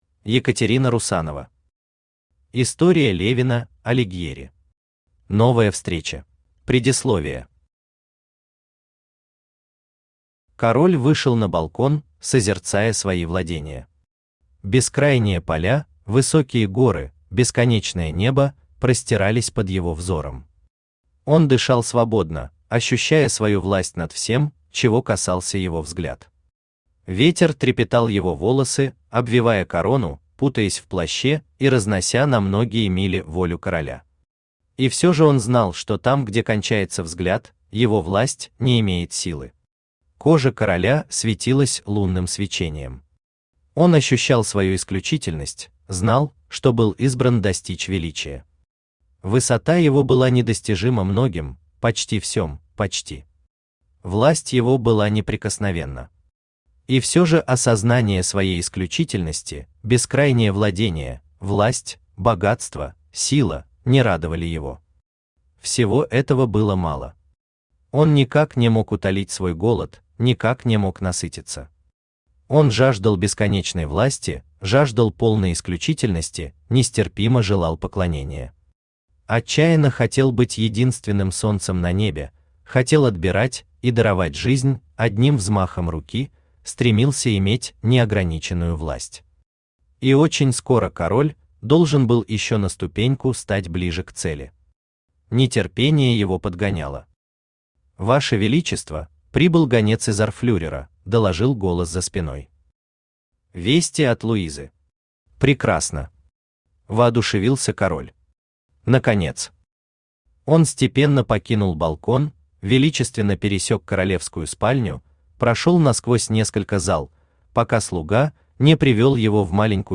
Аудиокнига История Левино Алигьери. Новая встреча | Библиотека аудиокниг
Новая встреча Автор Екатерина Владимировна Русанова Читает аудиокнигу Авточтец ЛитРес.